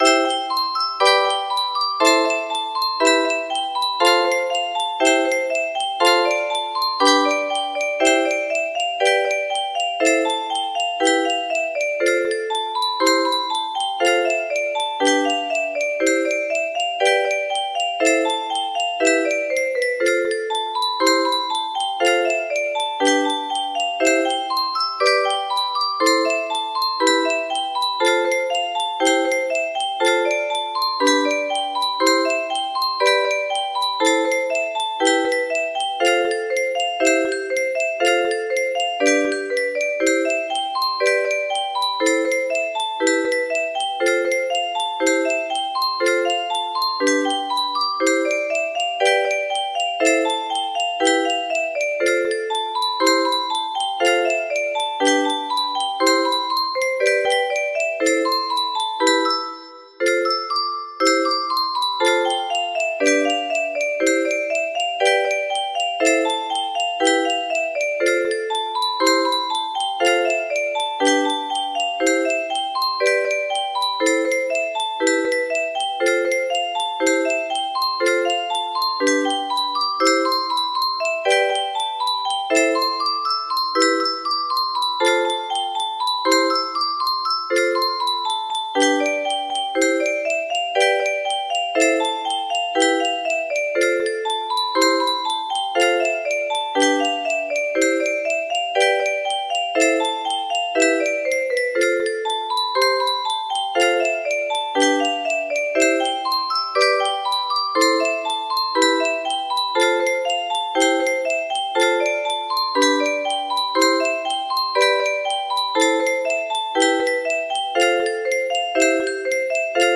Clone of Canon In D by Pachelbel music box melody